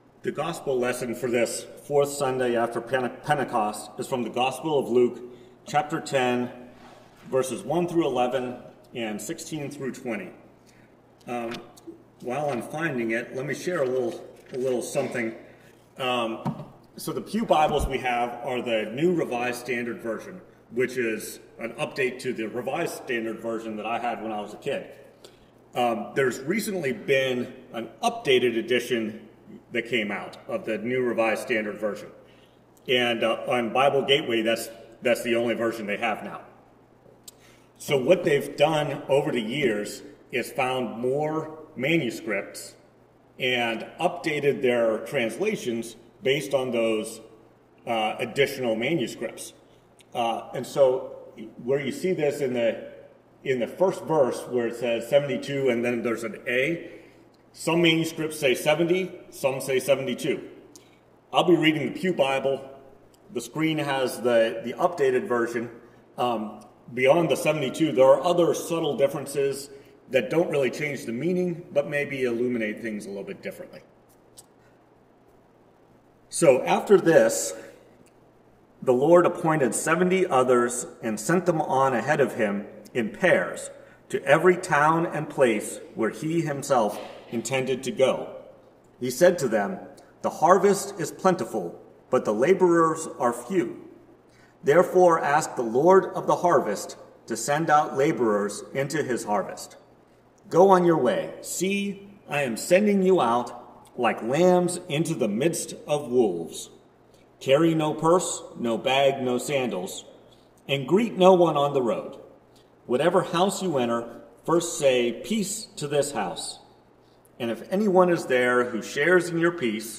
Preached at First Presbyterian Church of Rolla on July 3, 2022. Based on Luke 10:1-11, 16-20.